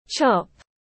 Thịt sườn tiếng anh gọi là chop, phiên âm tiếng anh đọc là /tʃɒp/
Chop /tʃɒp/